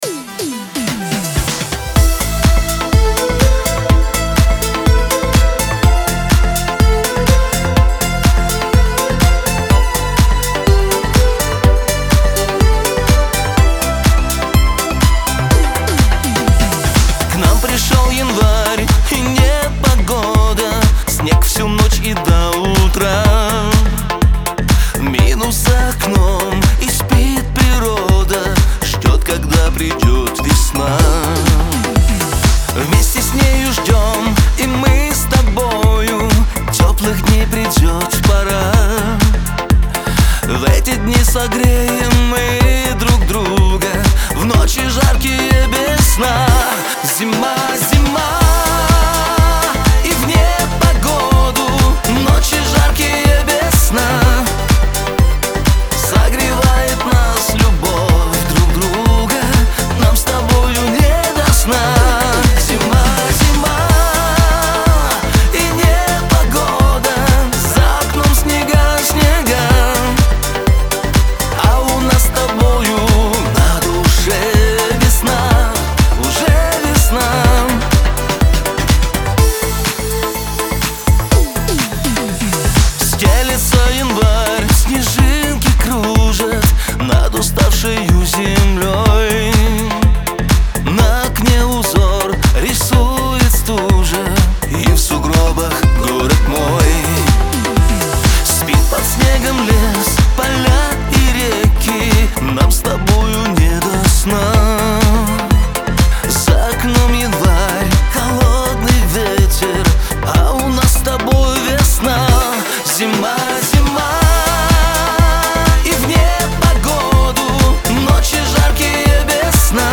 Лирика
эстрада
диско